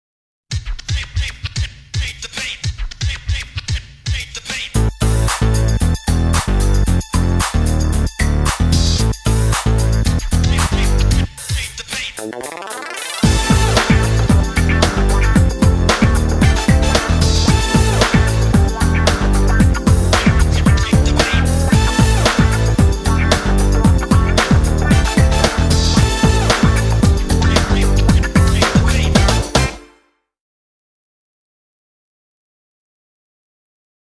• Category Hip Hop